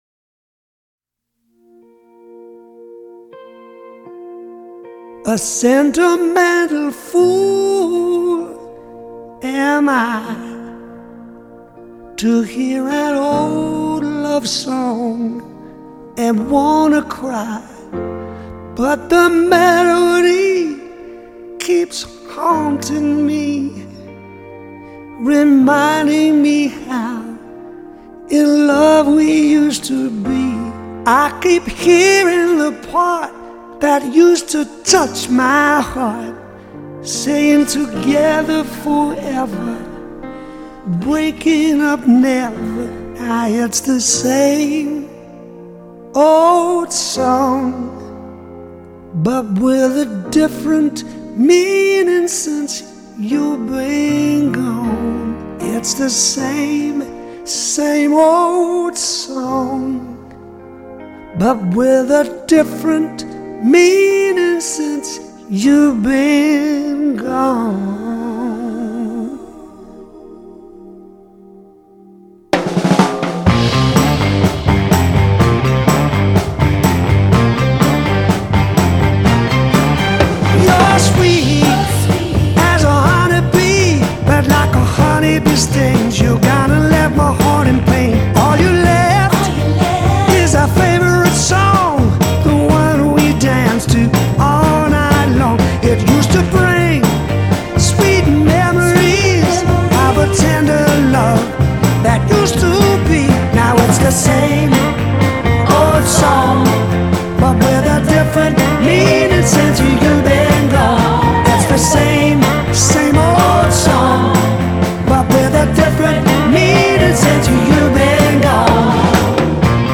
Genre: Pop/Rock